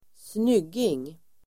Ladda ner uttalet
Uttal: [²sn'yg:ing]